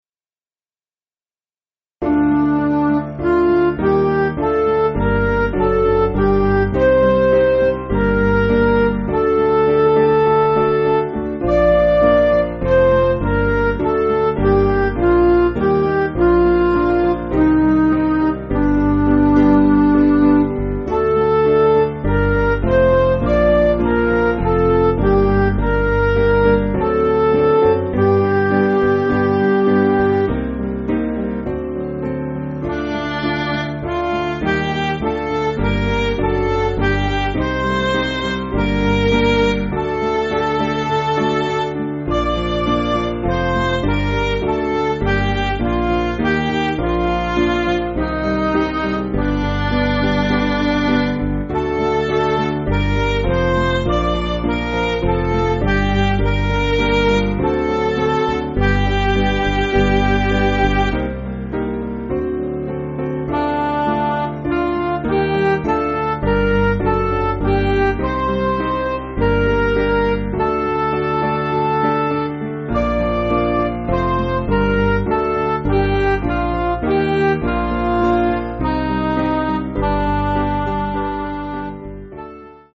Piano & Instrumental
(CM)   4/Gm